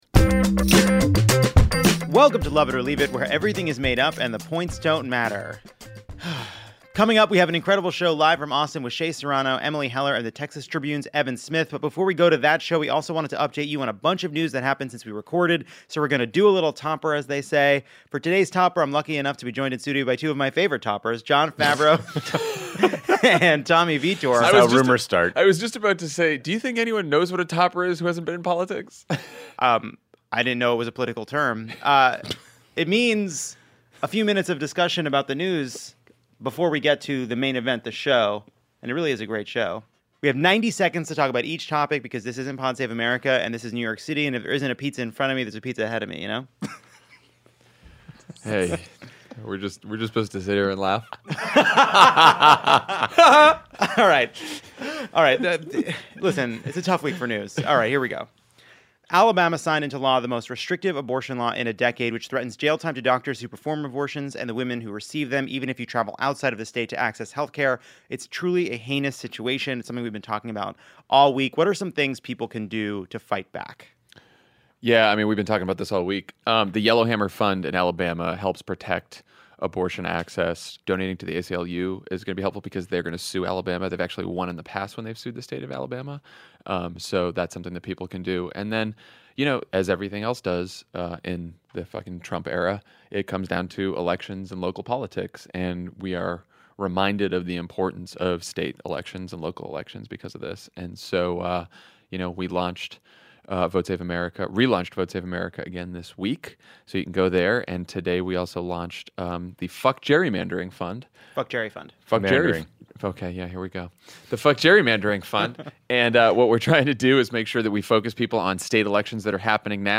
BBQ, Bombs, and de Blasio (LIVE from Austin)